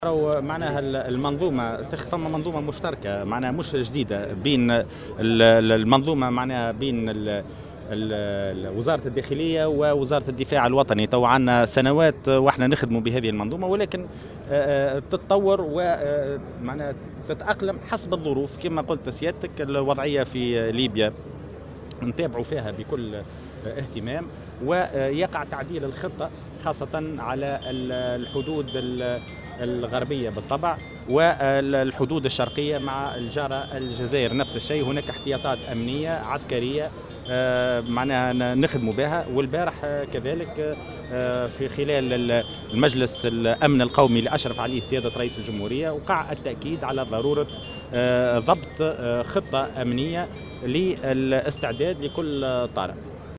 وأضاف في تصريح اليوم لـ"الجوهرة أف أم" على هامش زيارته لولاية المنستير بمناسبة إحياء ذكرى وفاة الزعيم الحبيب بورقيبة، أنه يتم متابعة الأوضاع في ليبيا بكل اهتمام، وأنه تم التأكيد أمس خلال اجتماع مجلس الأمن القومي الذي اشرف عليه رئيس الجمهورية، على ضرورة ضبط خطة أمنية استعدادا لأي طارئ.